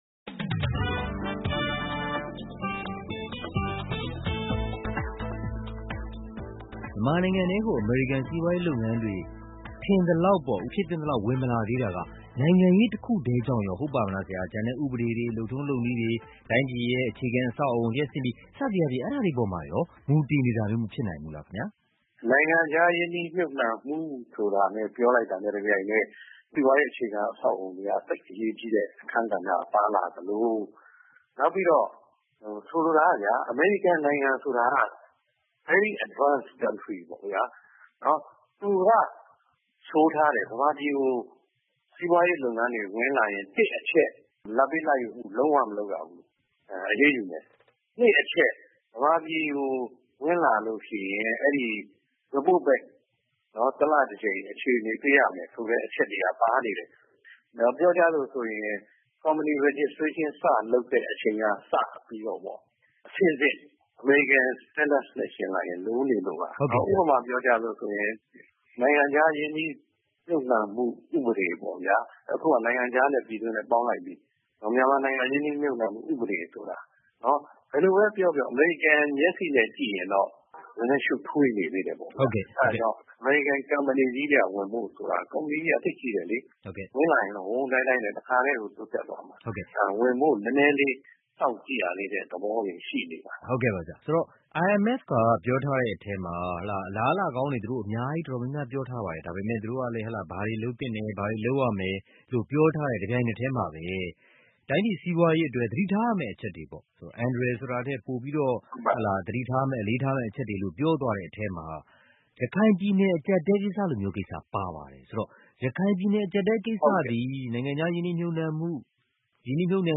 ဆက်သွယ်မေးမြန်းဆွေးနွေးထားတဲ့ ဒုတိယပိုင်းကို ဒီသီတင်းပတ် စီးပွားရေးကဏ္ဍမှာ